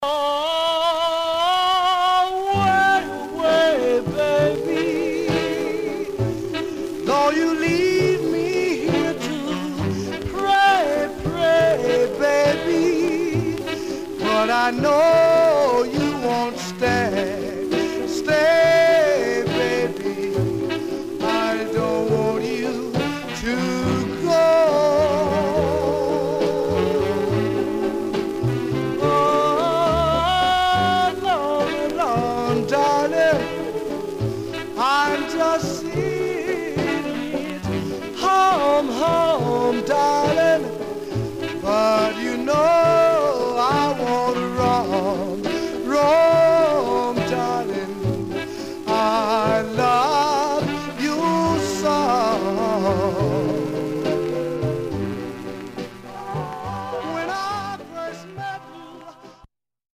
Condition Some surface noise/wear Stereo/mono Mono
Male Black Groups